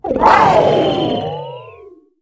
miraidon_ambient.ogg